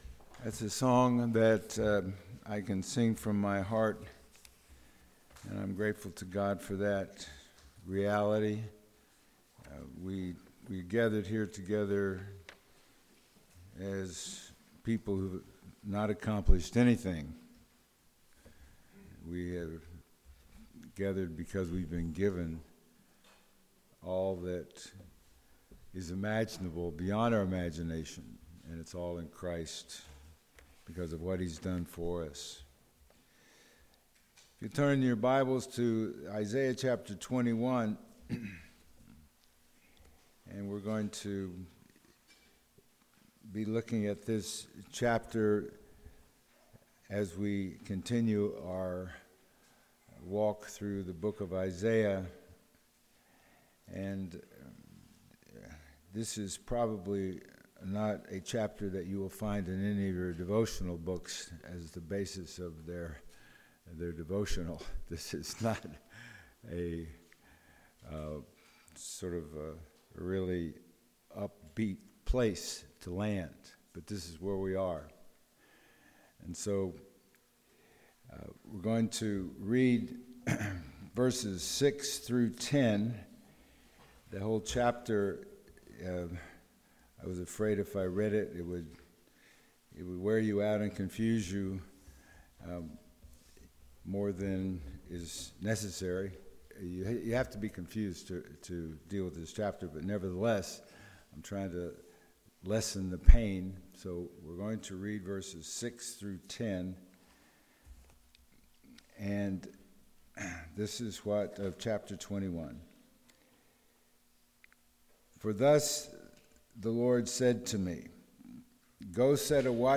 Sermons | The Bronx Household of Faith